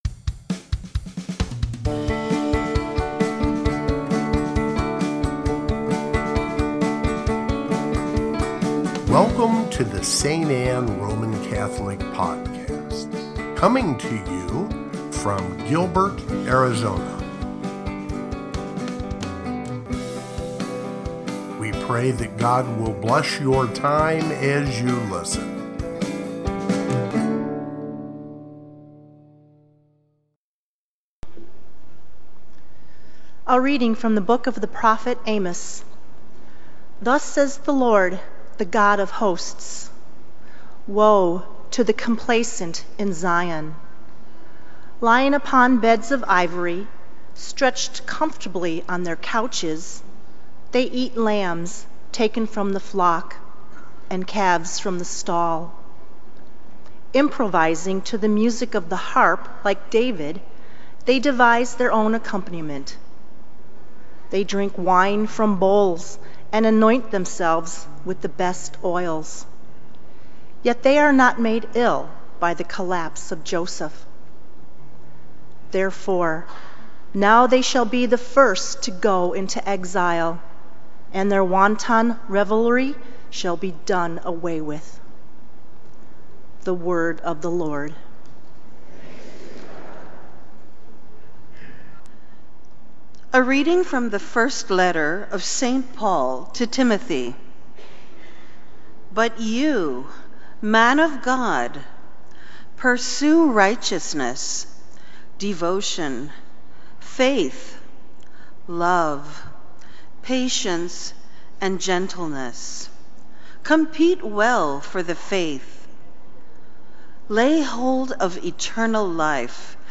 Eighteenth Sunday in Ordinary Time (Readings) Gospel , Readings , Ordinary Time August 04, 2019 · Jesus Christ Reading 1: Ecclesiastes 1:2; 2:21-23 Reading 2: Colossians 3:1-5, 9-11 Gospel: Luke 12:13-21 Read more Listen Download